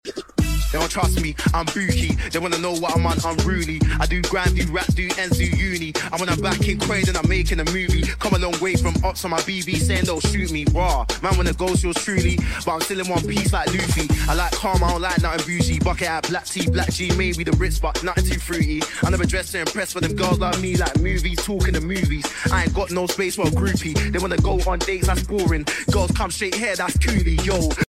Mash Up